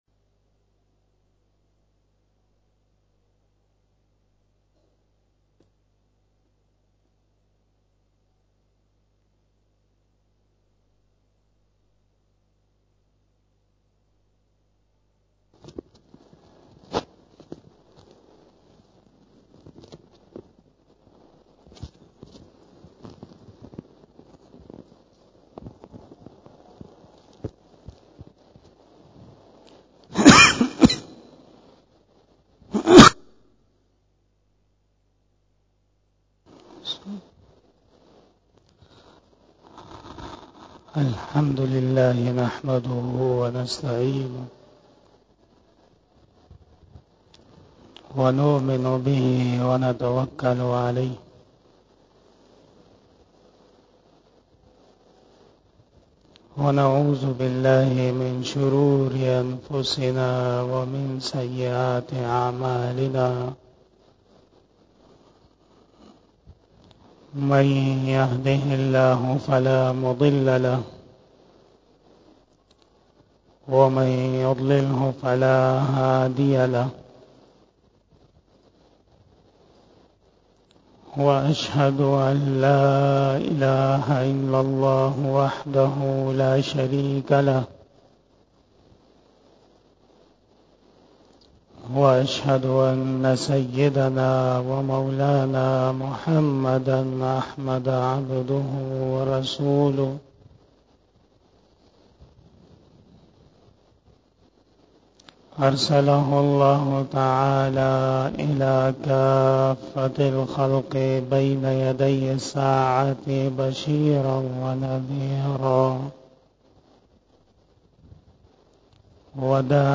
30 BAYAN E JUMMAH 28 July 2023 (09 Muharram ul Haraam 1445HJ)
Khitab-e-Jummah